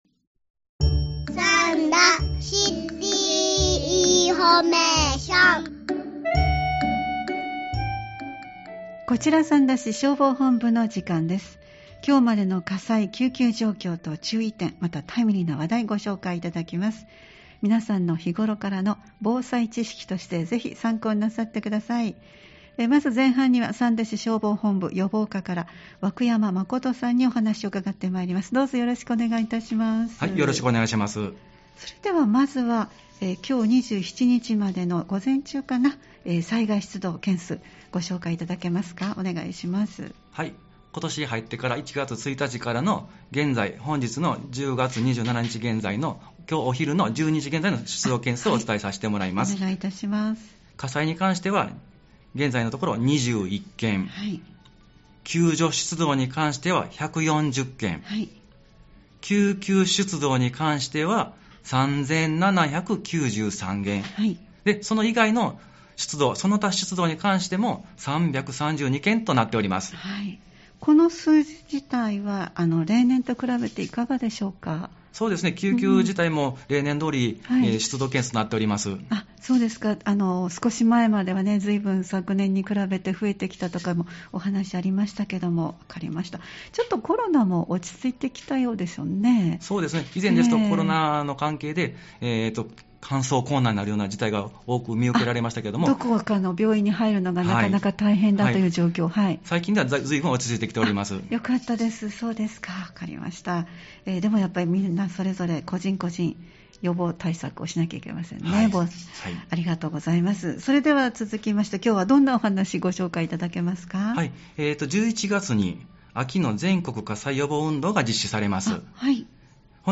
三田市消防本部から担当の方をお迎えして、火災・救急状況と注意点、防災情報や取り組みなどを紹介していただいています。